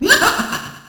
snd_joker_laugh0.ogg